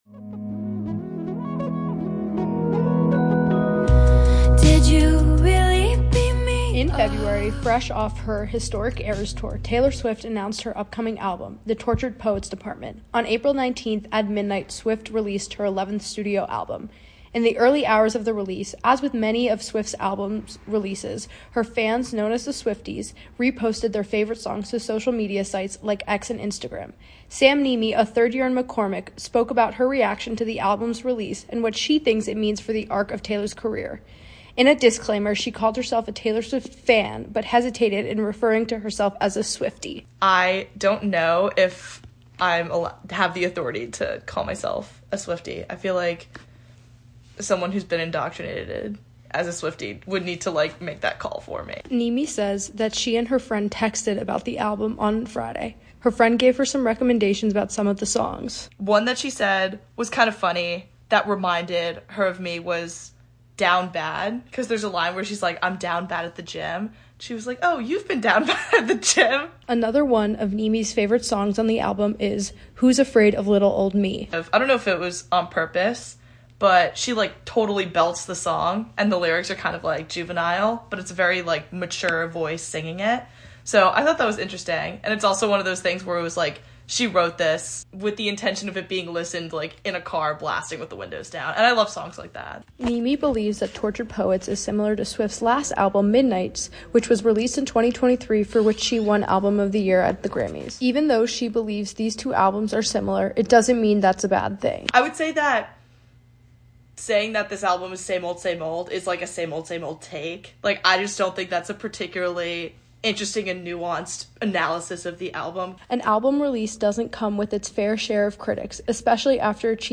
This past Friday, Taylor Swift released her newest album, “The Tortured Poet’s Department”. Students share their thoughts on her latest piece of work.